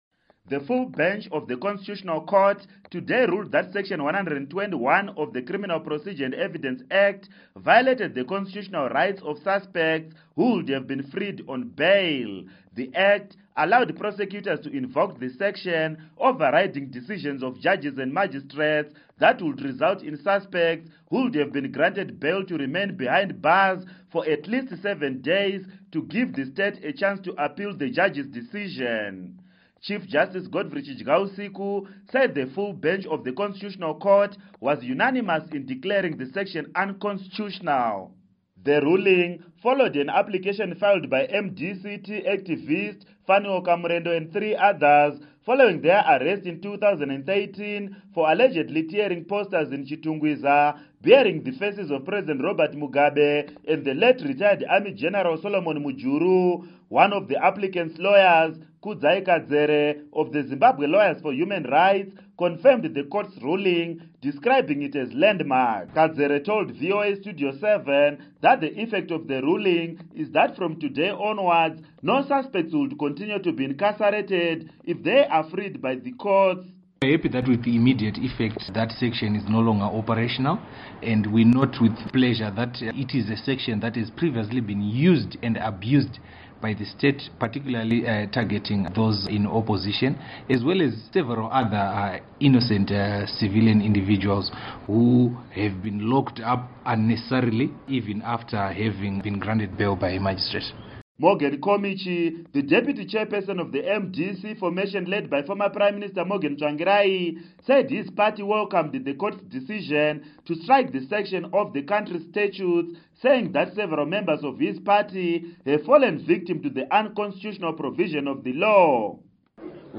Report on ConCourt Ruling